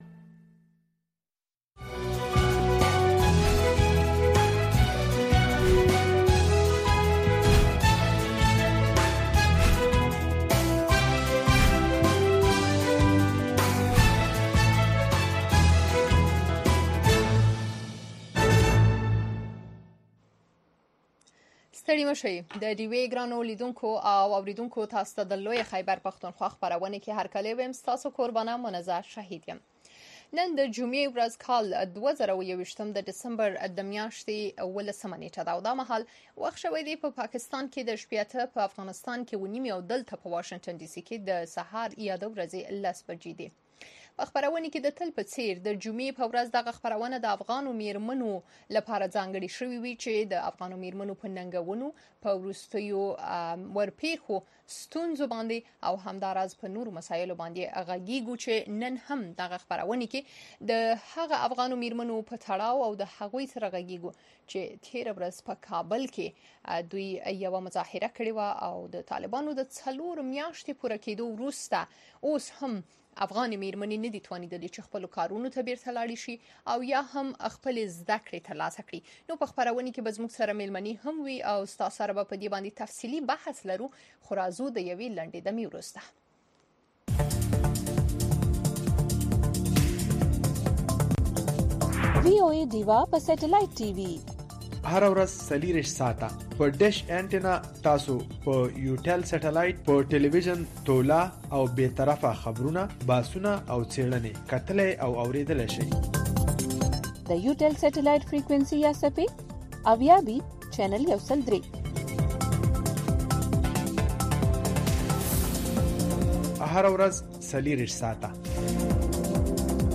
د وی او اې ډيوه راډيو خبرونه چالان کړئ اؤ د ورځې د مهمو تازه خبرونو سرليکونه واورئ.